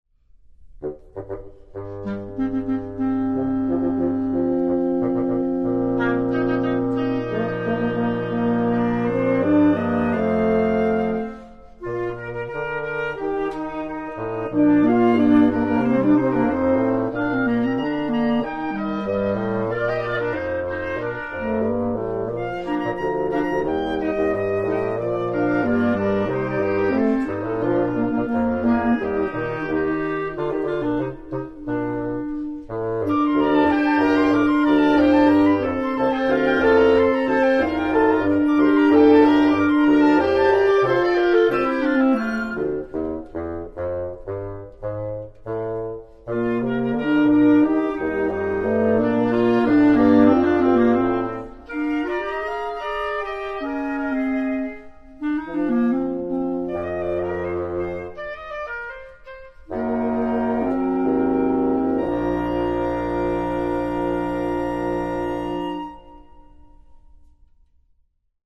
Blåsarkvintett – om en man i blåsväder…